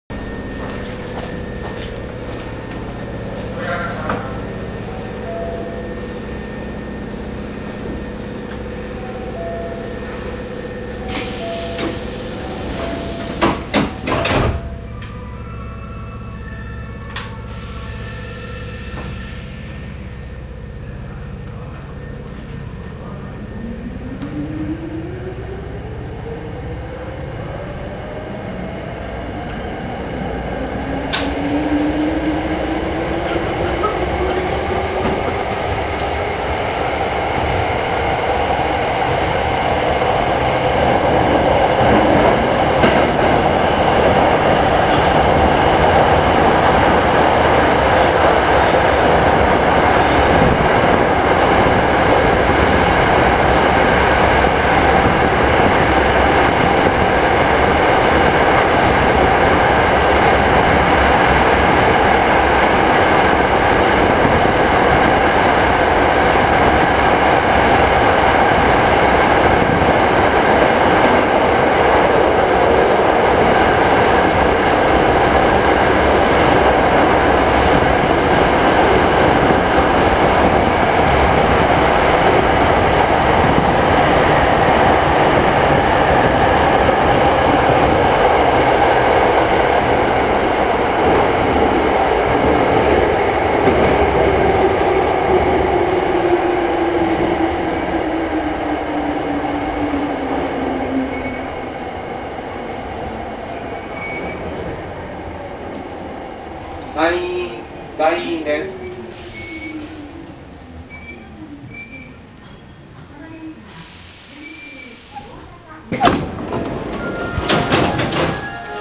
・3300系（更新車）走行音
【京都線】烏丸→西院（1分58秒：646KB）
走行装置が取り換えられたわけではないので、音自体はごく普通の抵抗制御車の音。地下だと少々にぎやかかもしれません。